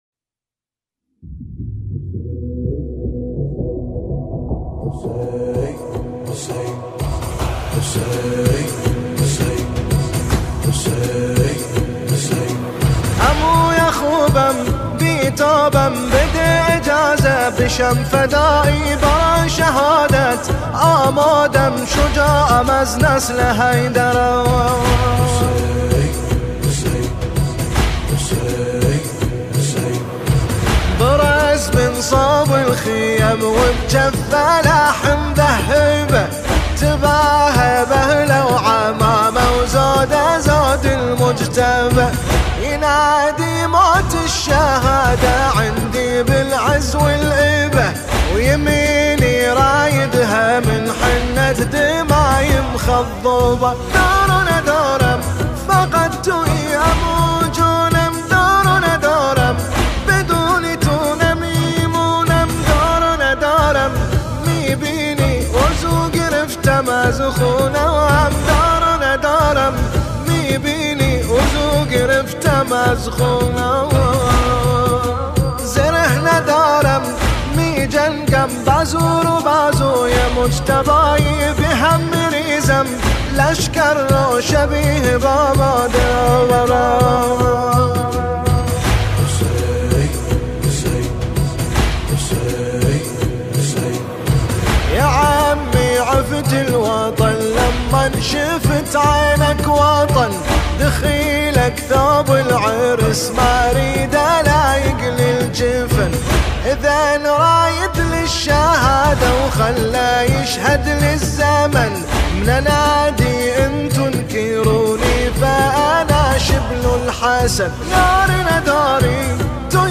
نماهنگ
محرم 1399